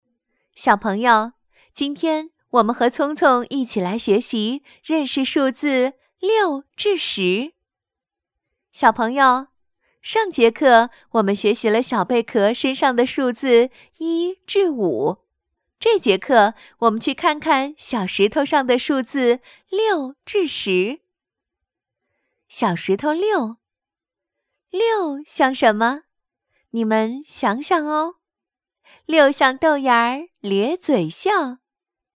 女国12温柔舒缓配音-新声库配音网
女国12_多媒体_培训课件_数字.mp3